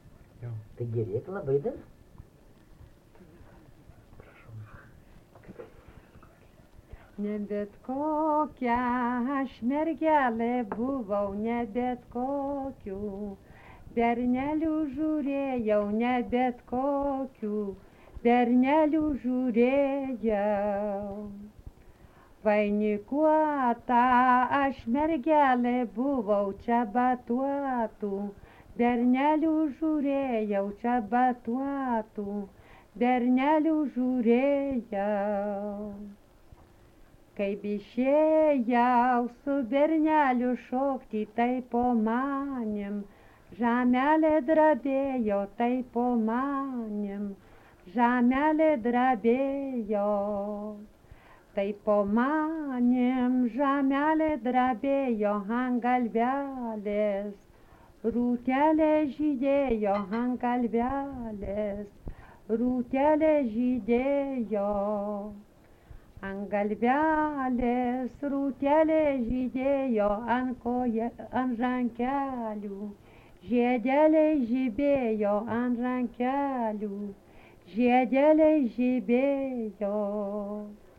Dalykas, tema daina
Atlikimo pubūdis vokalinis